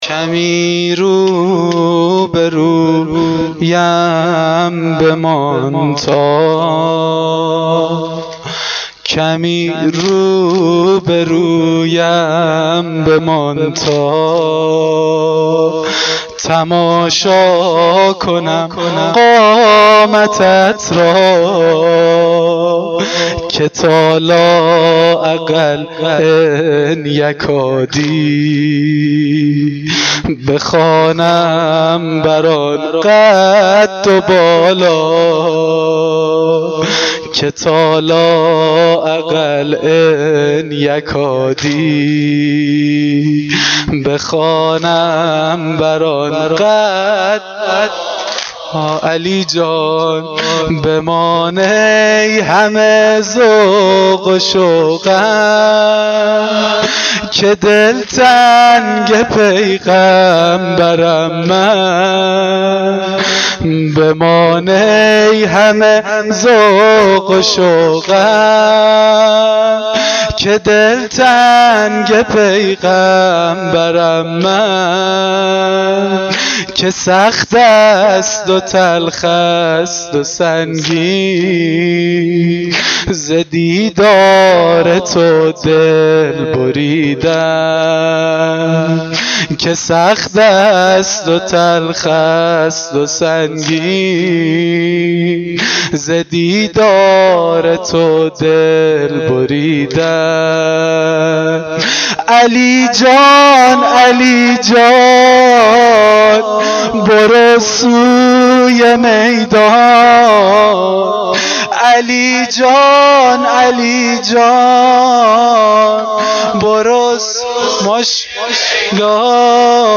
واحد سنگین شب هشتم محرم1393